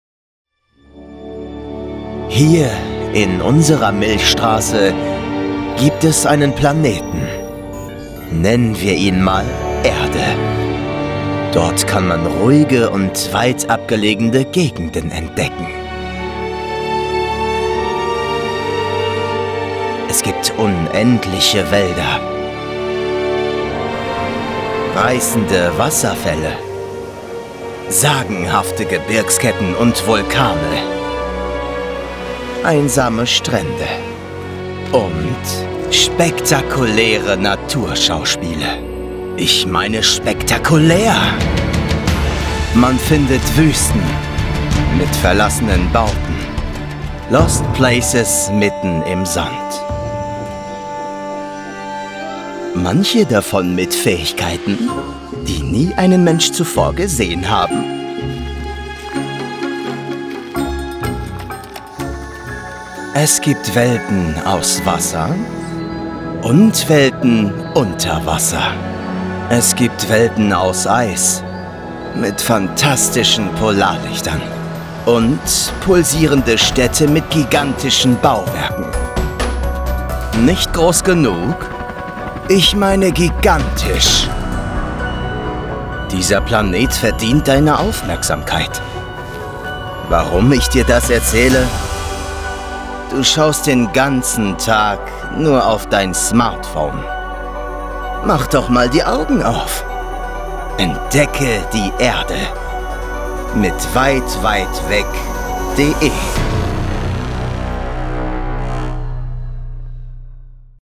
Planet Earth Docu
Young Adult
Acoustics: Vocal booth including Caruso Iso Bond 10cm, Basotect for acoustic quality.